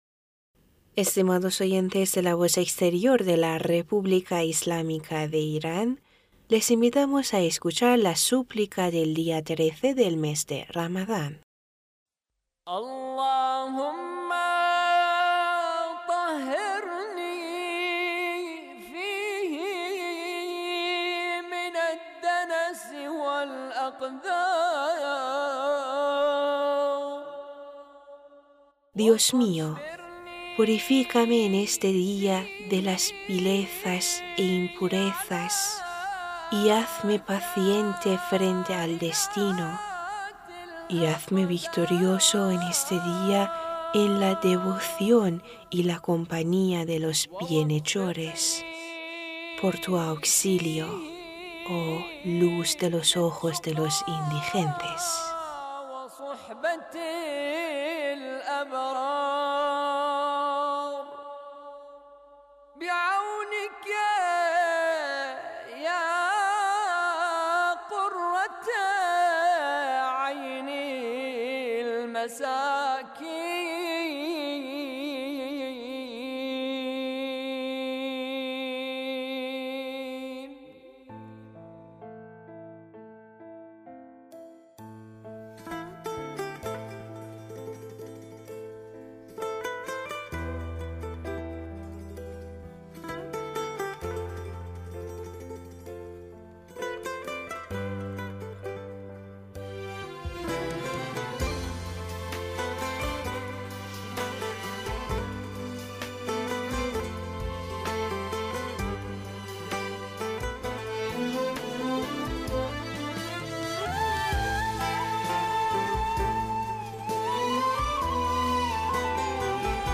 Súplica de 13 día de mes de Ramadán